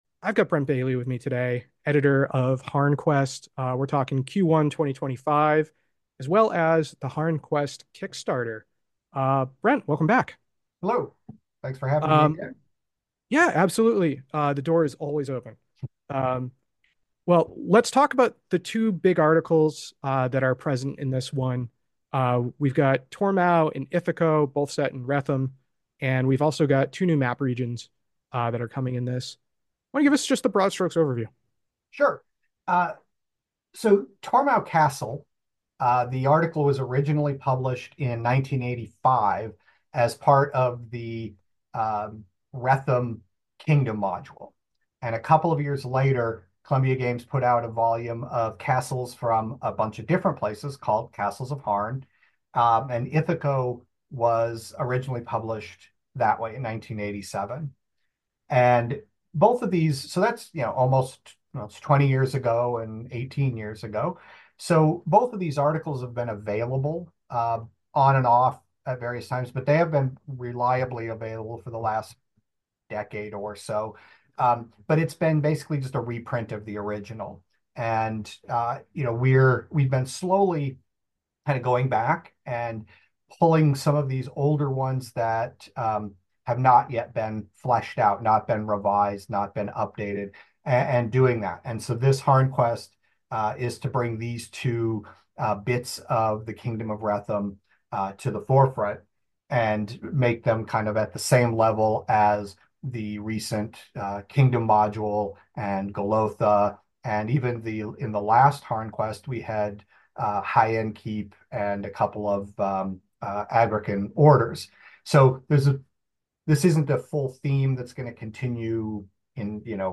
I'm joined by writers and editors for the Chybisa hardback kickstarter!